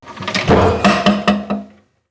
Cerrar la tapa de un baúl 02 (más fuerte)
brusquedad
Sonidos: Acciones humanas
Sonidos: Hogar